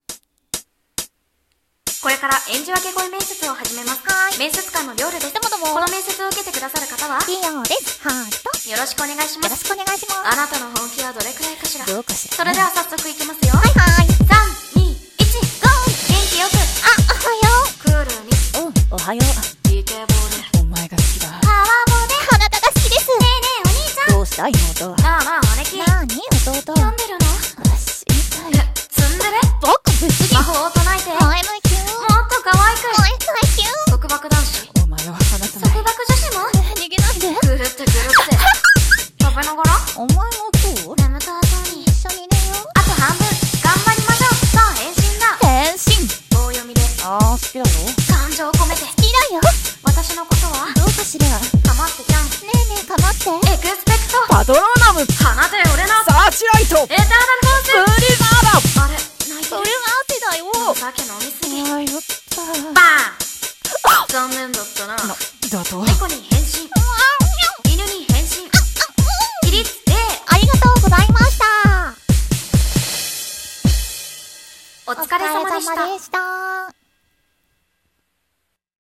【声面接】演じ分け声面接